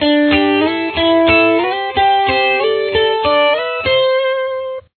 Here are some examples of some licks that you could create with it: